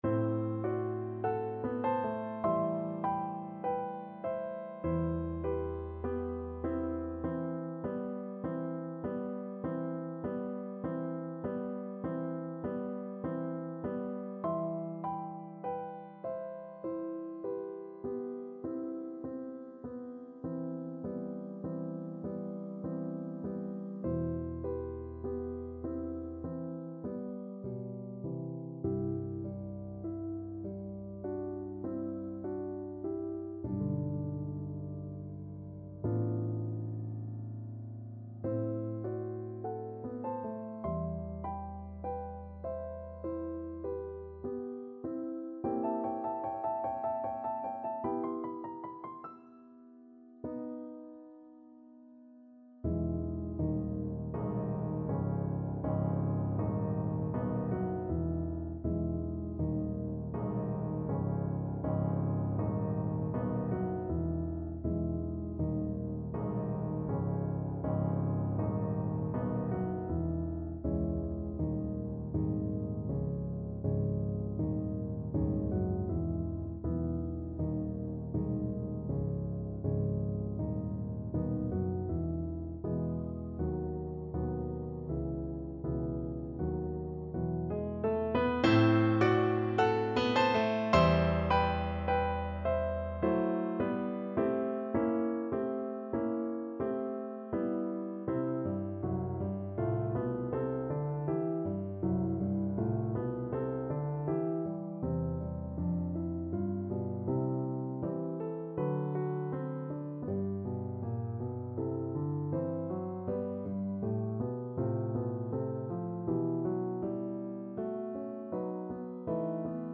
Play (or use space bar on your keyboard) Pause Music Playalong - Piano Accompaniment Playalong Band Accompaniment not yet available reset tempo print settings full screen
C major (Sounding Pitch) (View more C major Music for Voice )
4/4 (View more 4/4 Music)
Andantino (=50) (View more music marked Andantino)
Classical (View more Classical Voice Music)